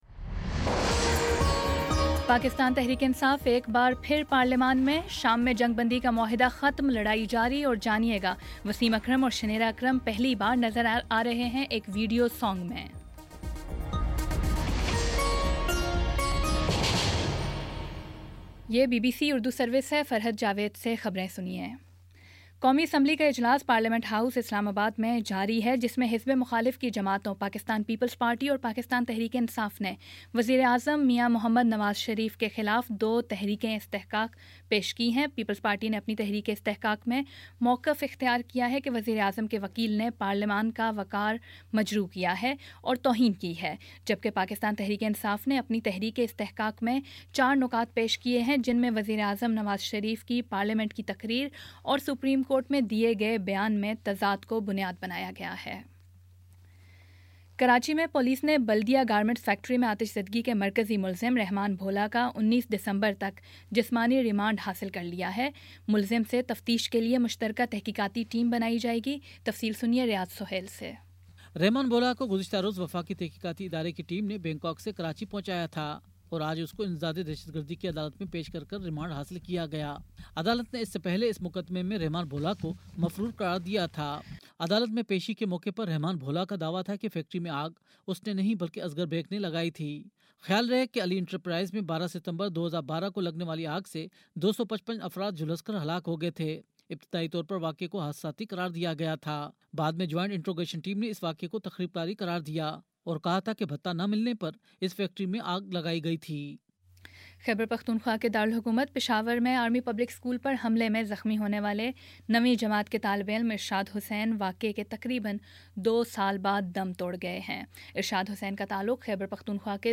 دسمبر 14 : شام پانچ بجے کا نیوز بُلیٹن